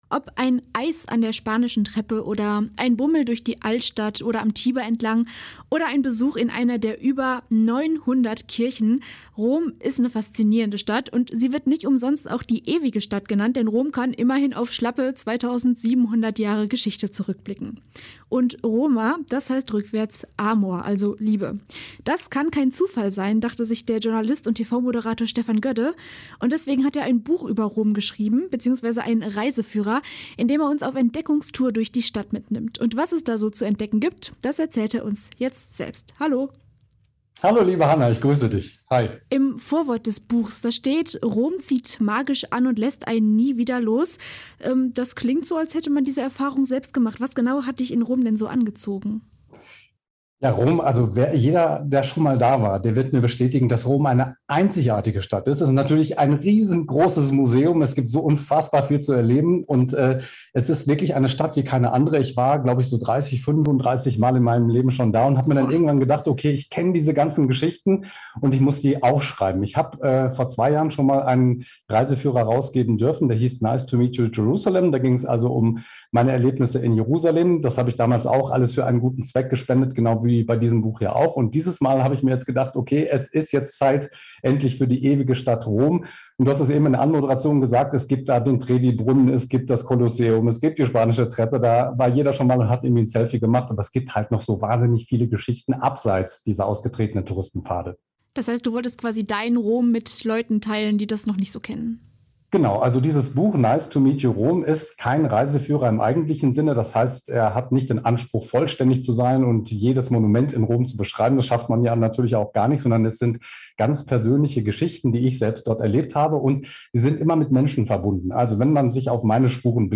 Ein Interview mit Stefan Gödde (Journalist und TV-Moderator)